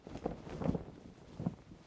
cloth_sail5.R.wav